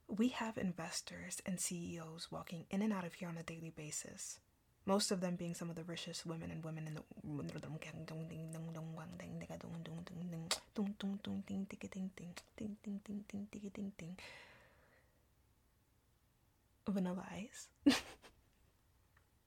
Blooper